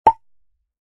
Pop In Sound Effect
Notification Sounds / Sound Effects
Pop-in-sound-effect.mp3